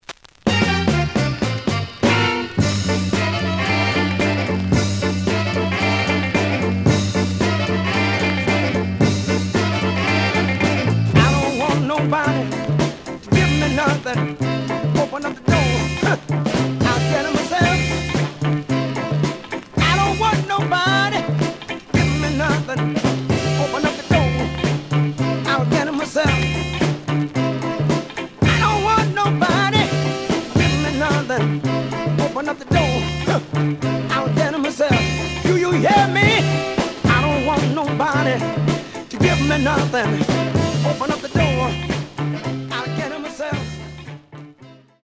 The audio sample is recorded from the actual item.
●Genre: Funk, 60's Funk
Some noise on beginning of A side.